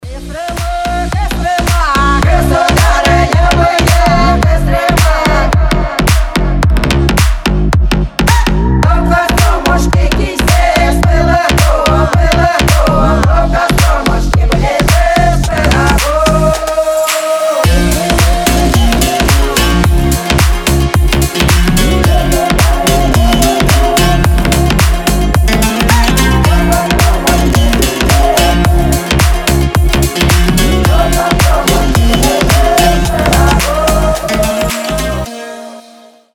Народные
хор
ремиксы
slap house